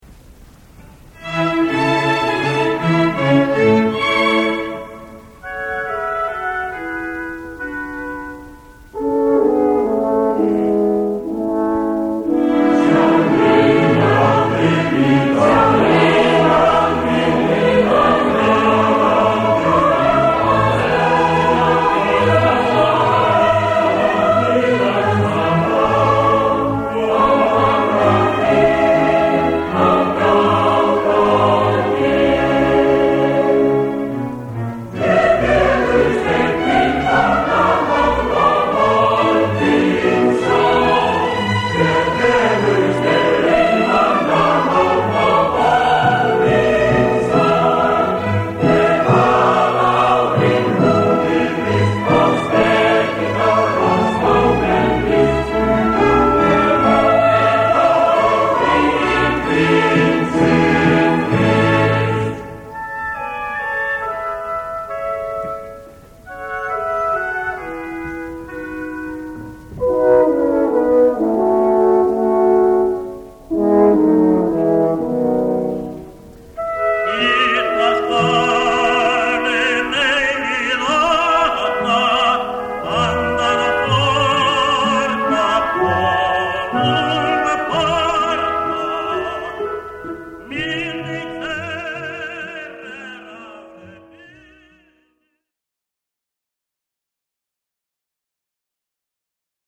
Kór með Tenór sóló